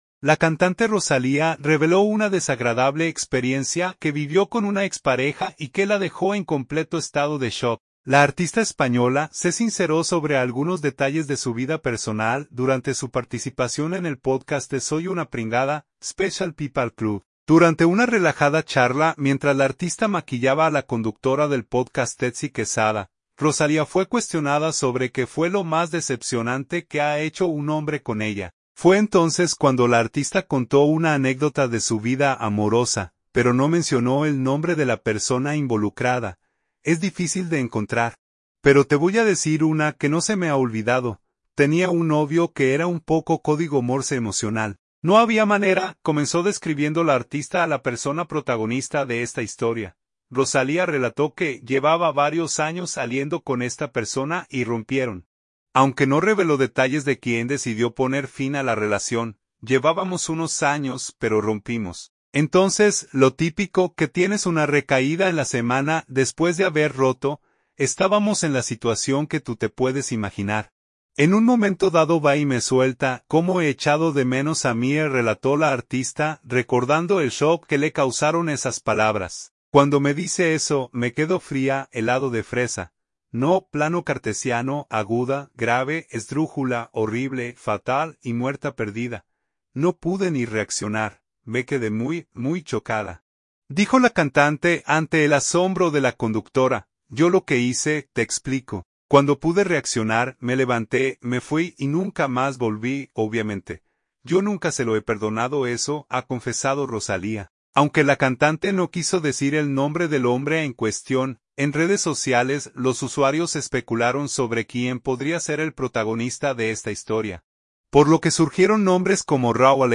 La artista española se sinceró sobre algunos detalles de su vida personal durante su participación en el pódcast de Soy una pringada, ‘Special People Club’.